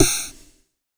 SNARE 30  -L.wav